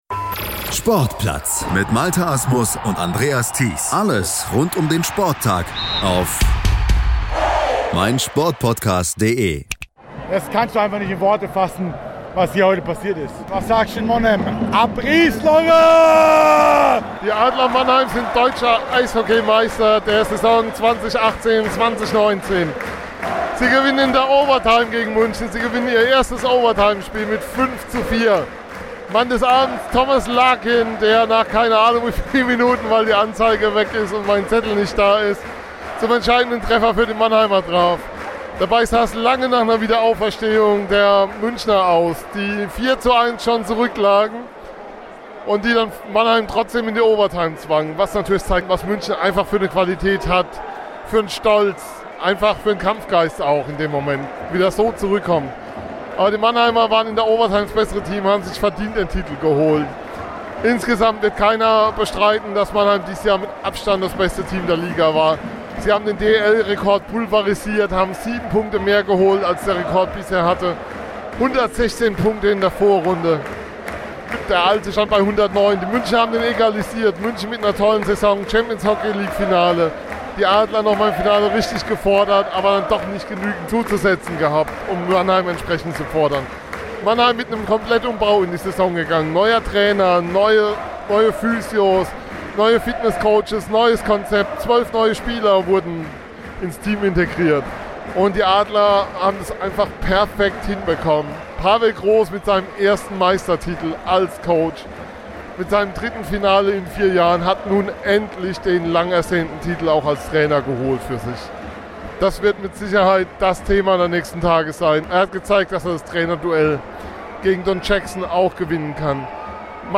war vor Ort in der SAP Arena